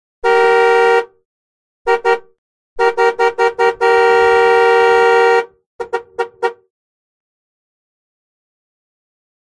Horn Honk; Mid-sized Car Horn. Long, Medium And Short Honks. Close Perspective.